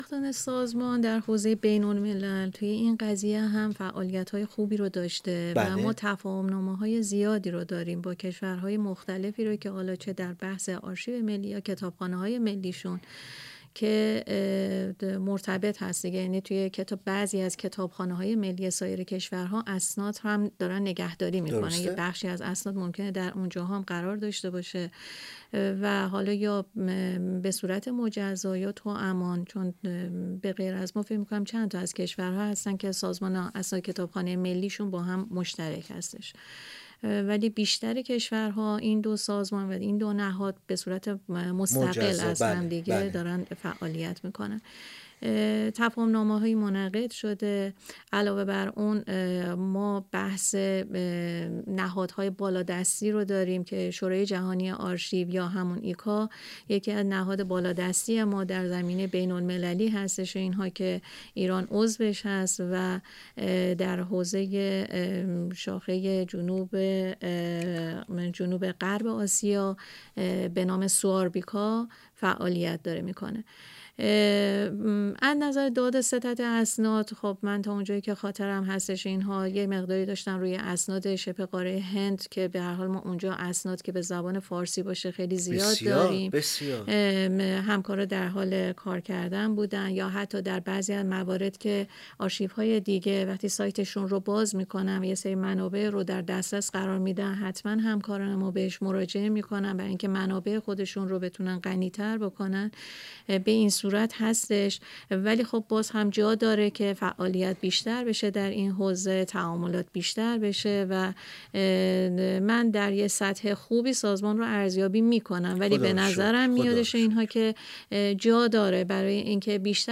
میزگرد ایکنا به مناسبت روز اسناد ملی و میراث مکتوب/ 2